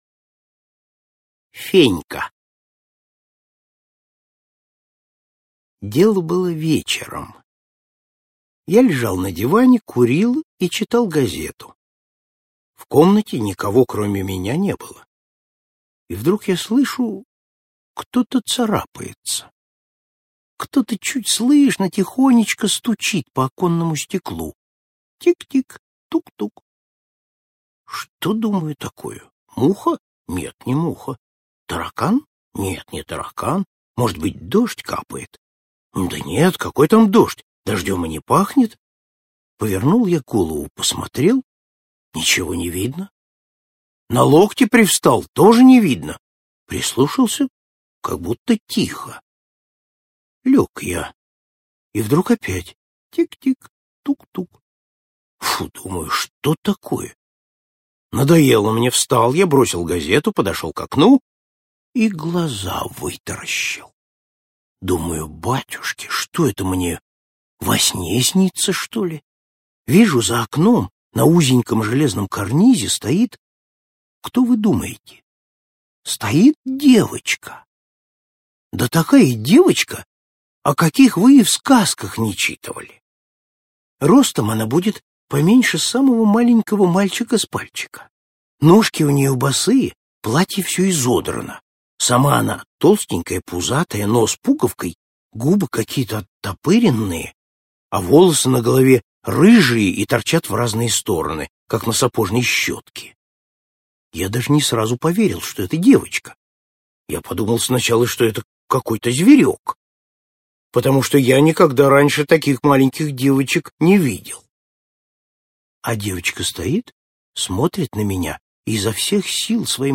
Слушайте Фенька - аудио рассказ Пантелеева Л. Рассказ про очень маленькую девочку Феньку, которая постучалась в окно к автору.